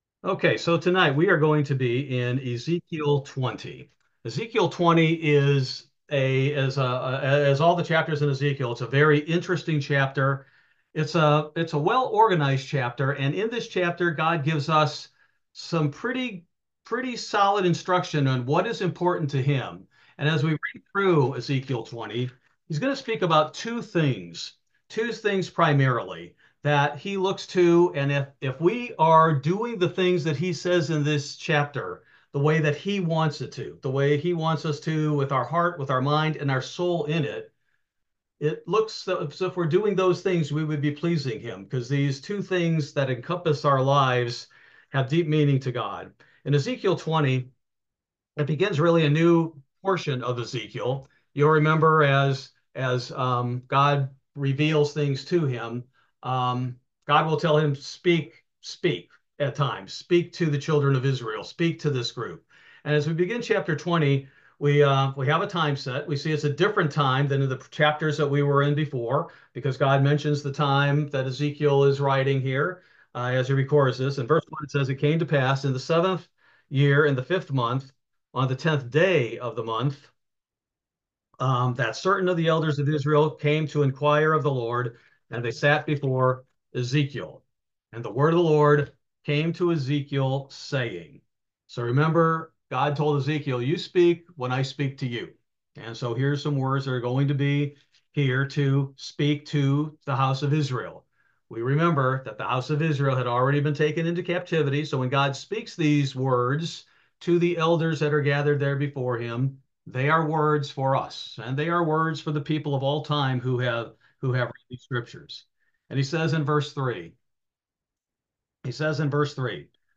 Bible Study: September 25, 2024